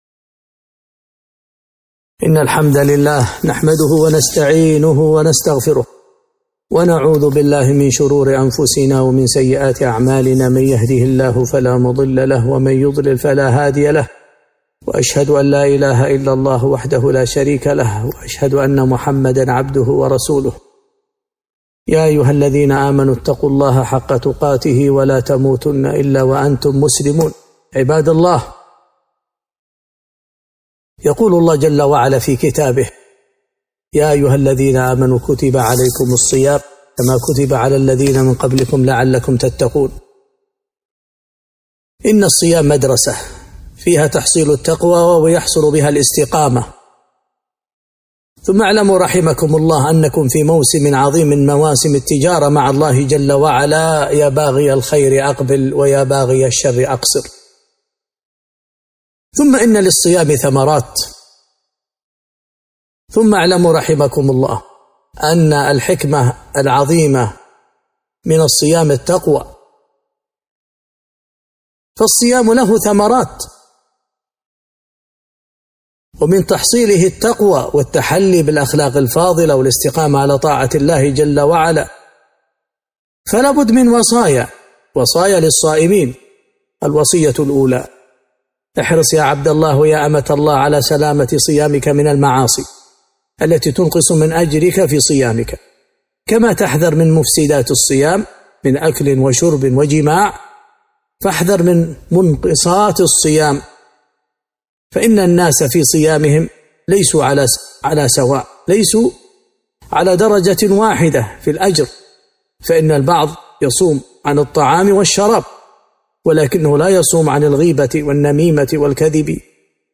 خطبة - وصايا ذهبية للصائمين